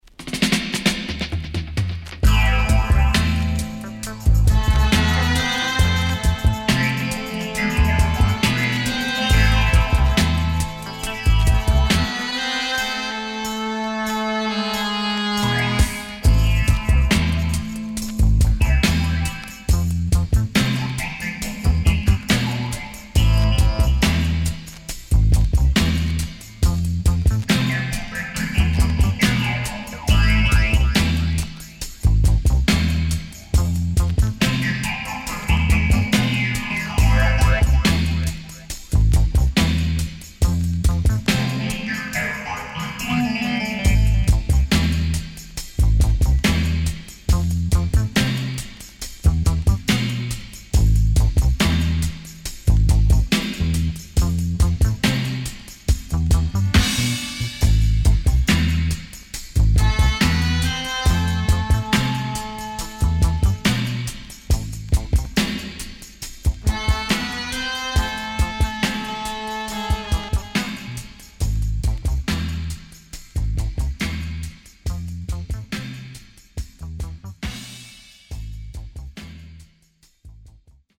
HOME > REISSUE [REGGAE / ROOTS]
渋Harmony on Heavy Track